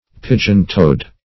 Search Result for " pigeon-toed" : Wordnet 3.0 ADJECTIVE (1) 1. having feet that turn inward ; The Collaborative International Dictionary of English v.0.48: Pigeontoed \Pi"geon*toed`\, a. Having the toes turned in.